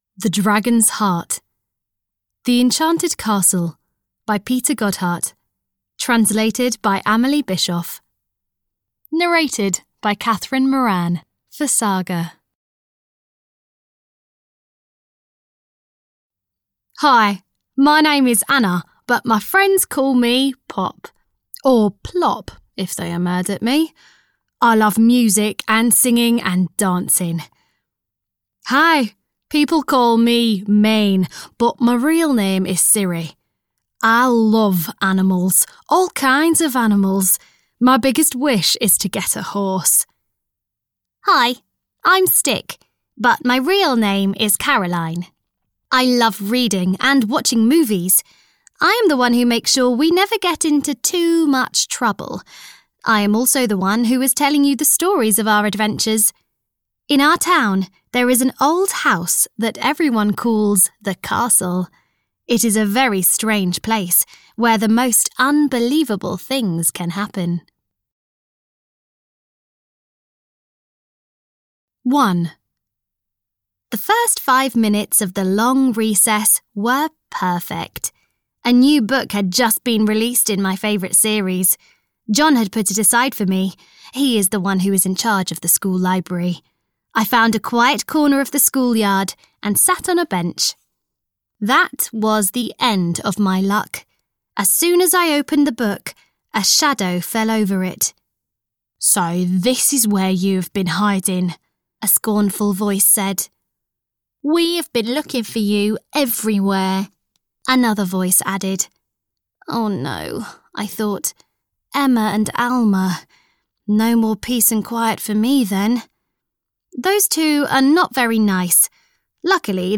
The Enchanted Castle 10 - The Dragon's Heart (EN) audiokniha
Ukázka z knihy